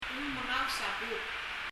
発音
mengaus　　[mɛŋaus]　　　　編む weave
bub　　[bu(p)]　　　　　　　　　　（魚をとる）わな　trap
（語尾の b はほとんど聞こえません）